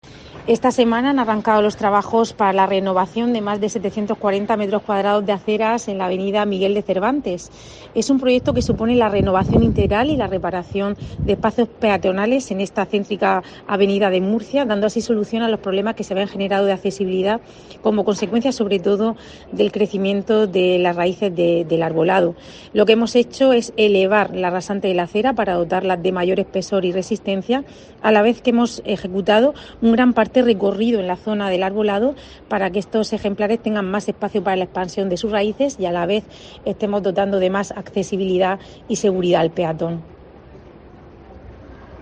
Rebeca Pérez, vicealcaldesa y concejal de Fomento y Patrimonio